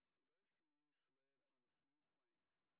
sp01_street_snr20.wav